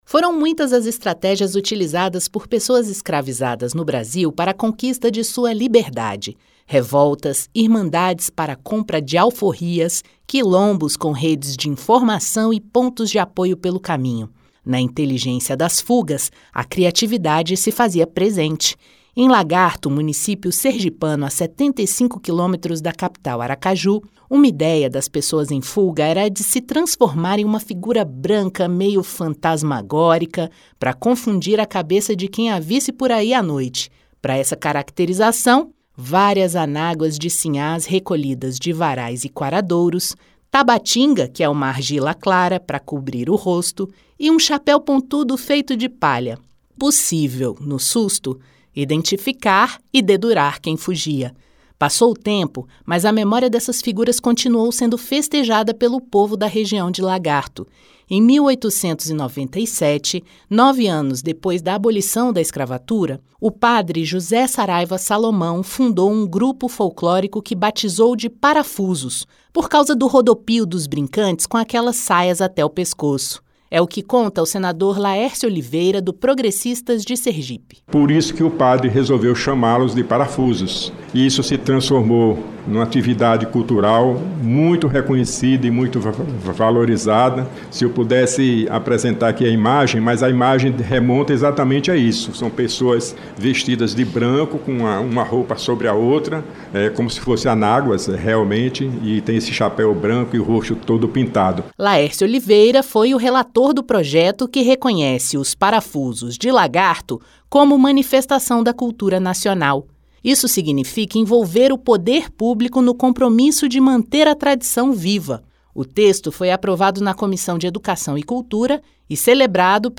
Trilha Sonora